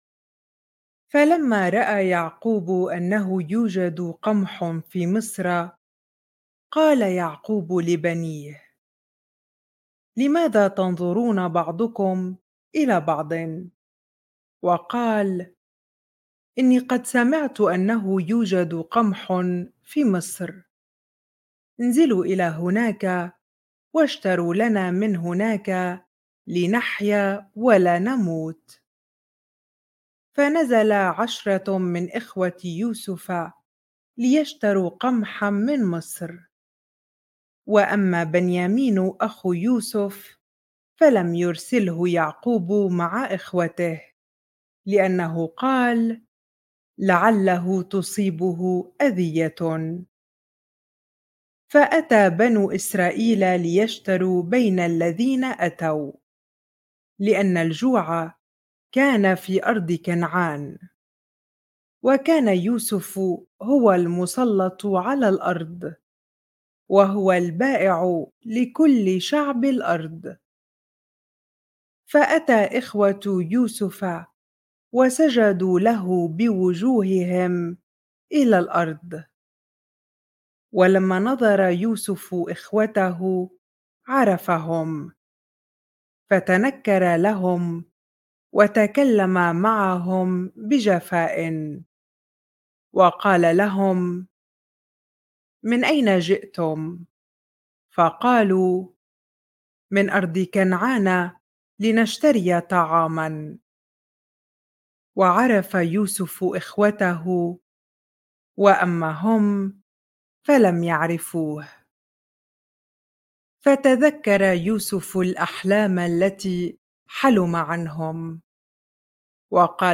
bible-reading-genesis 42 ar